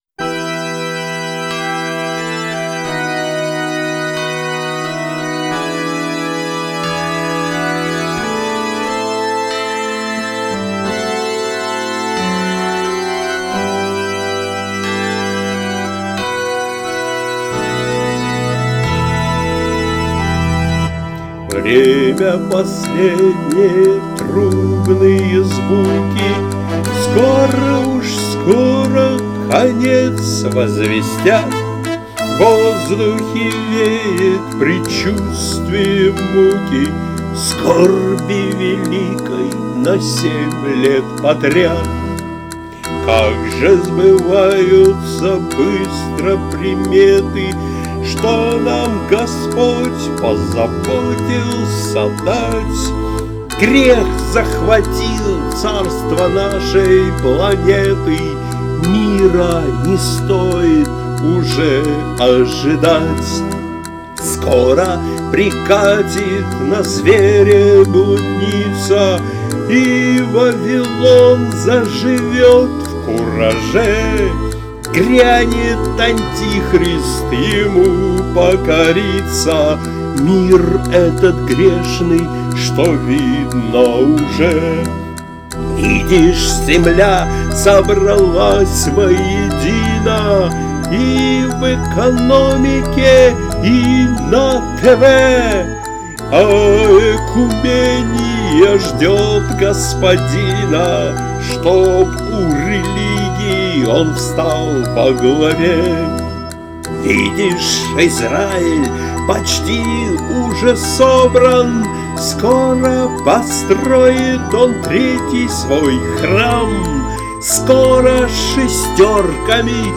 Христианские музыкальные песни